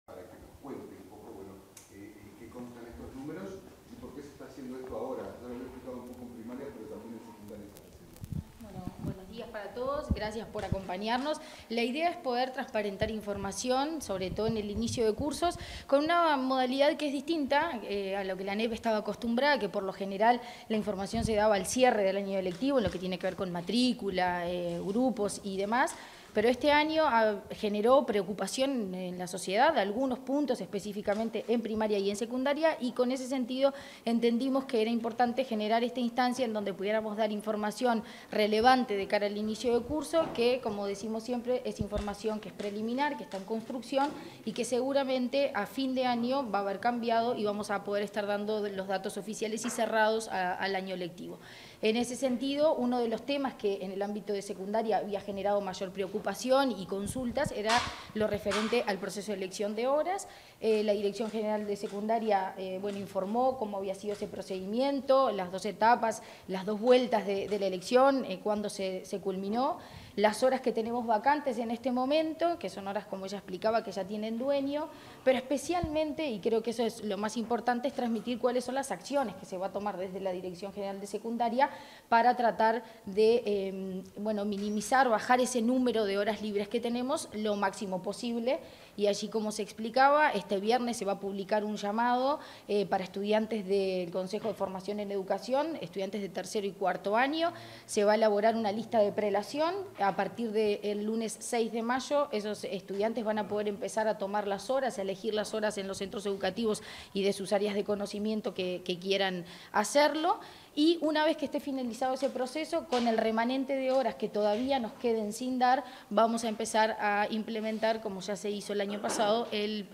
Declaraciones de la presidenta de ANEP, Virginia Cáceres
Declaraciones de la presidenta de ANEP, Virginia Cáceres 16/04/2024 Compartir Facebook X Copiar enlace WhatsApp LinkedIn Tras presentar datos sobre inicio de cursos, este 16 de abril, la presidenta del Consejo Directivo Central (Codicen) de la Administración Nacional de Educación Pública (ANEP), Virginia Cáceres, realizó declaraciones a la prensa.